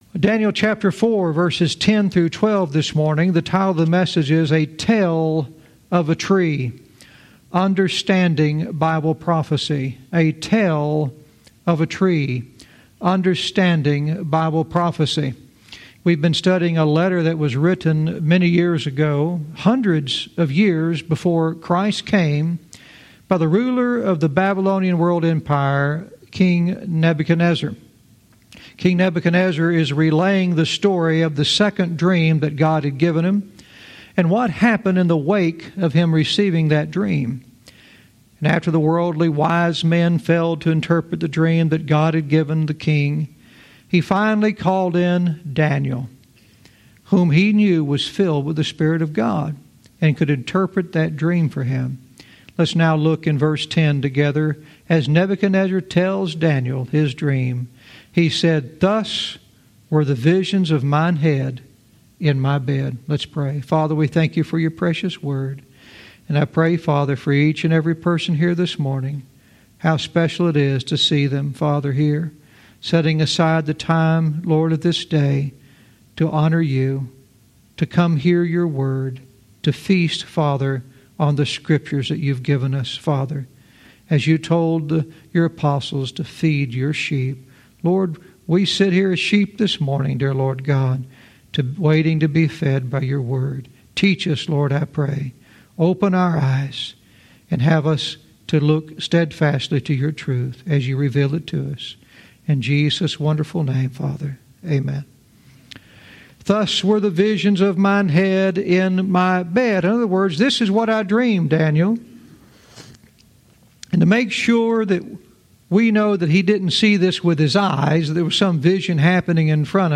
Verse by verse teaching - Daniel 4:10-12 " A Tell of a Tree"